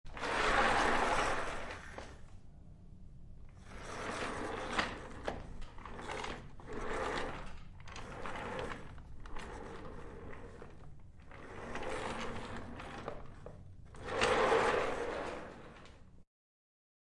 Tiếng Ghế bàn làm việc có bánh xe kêu
Thể loại: Tiếng đồ vật
Description: Tiếng ghế bàn làm việc có bánh xe kêu lạch cạch, rít nhẹ hay kèn kẹt vang lên mỗi khi di chuyển trên sàn. Âm thanh này có thể được mô tả như tiếng lăn, tiếng ma sát, tiếng lọc cọc hoặc tiếng bánh xe xoay khô dầu.
tieng-ghe-ban-lam-viec-co-banh-xe-keu-www_tiengdong_com.mp3